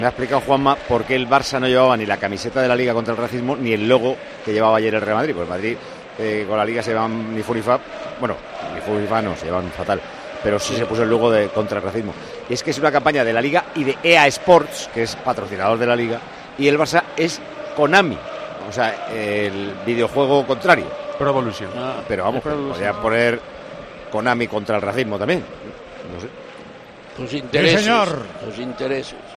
Tal y como ha contado Paco González en Tiempo de Juego durante la retransmisión del encuentro, y tras recibir un mensaje de Juanma Castaño, la razón es principalmente por motivos de patrocinadores.